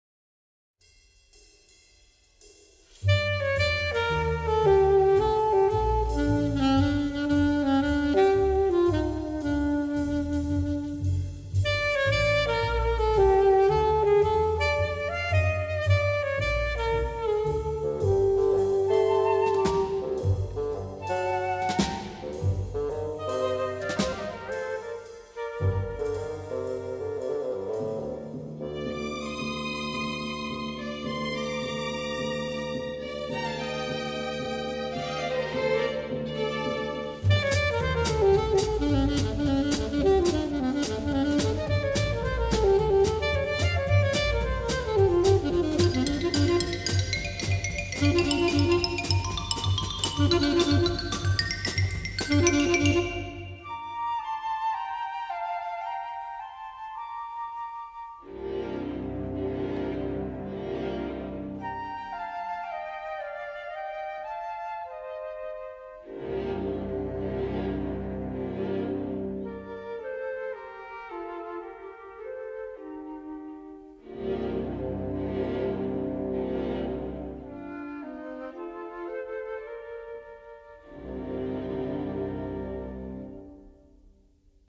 recorded at Abbey Road Studios in London, England